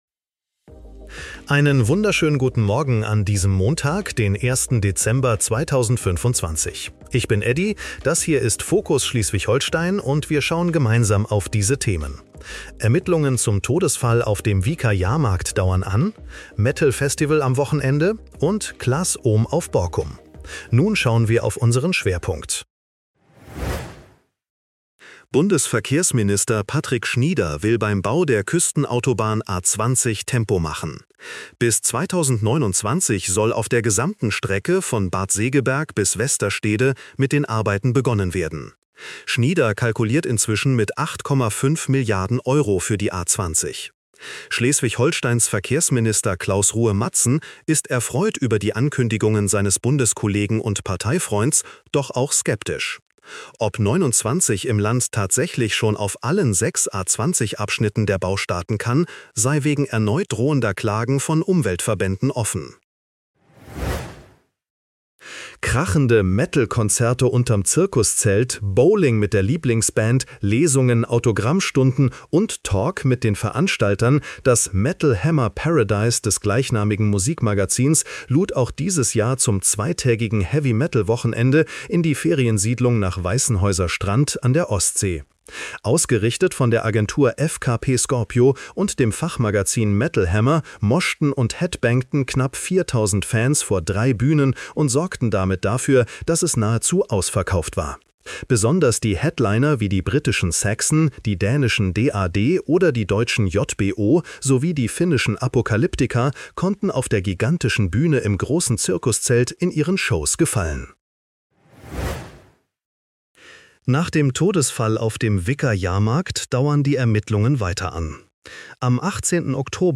Nachrichten-Podcast bekommst Du ab 7:30 Uhr die wichtigsten Infos